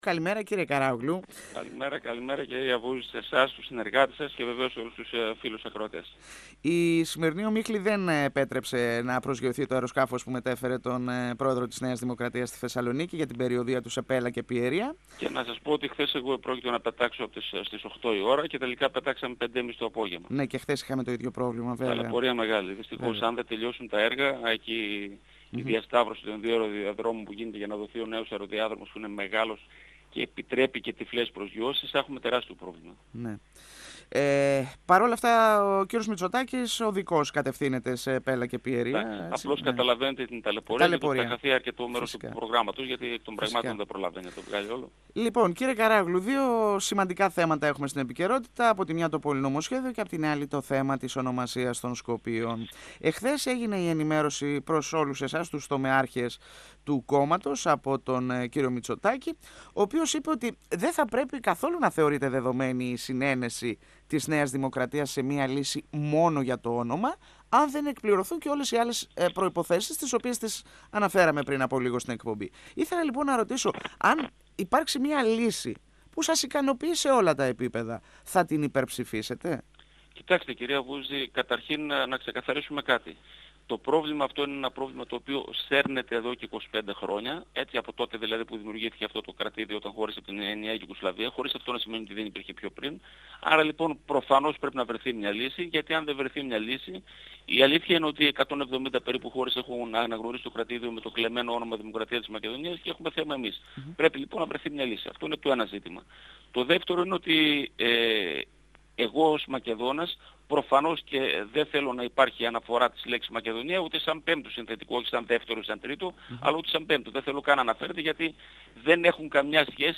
10Ιαν2018 – Ο βουλευτής Β’ Θεσσαλονίκης της ΝΔ Θεόδωρος Καράογλου στον 102 fm της ΕΡΤ3